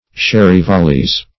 Search Result for " sherryvallies" : The Collaborative International Dictionary of English v.0.48: Sherryvallies \Sher"ry*val`lies\, n. pl.